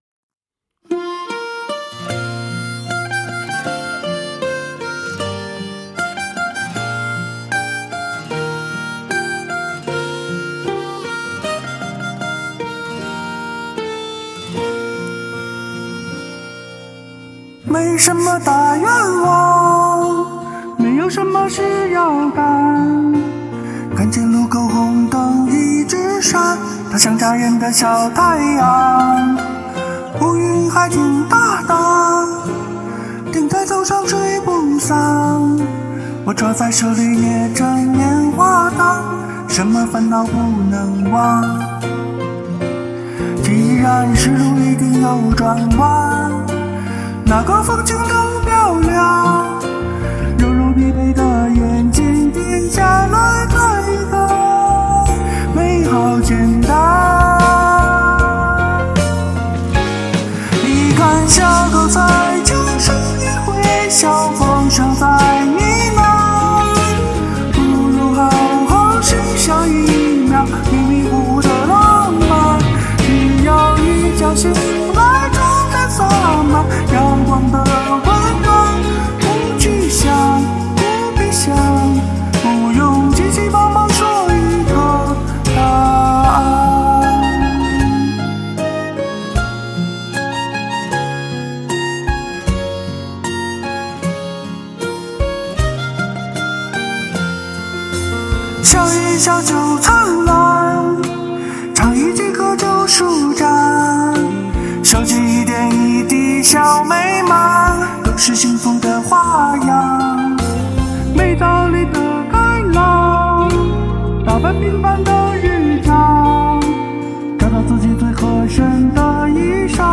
好听的演唱，情感超级投入！
尤其那气声与真声的丝滑转换，仿佛灯火阑珊处的烟火花影，每一处转音都藏着惊喜。
而结尾“自己就是自己最好的陪伴”的渐弱处理，又似余温未散的元宵汤碗，留人久久回味。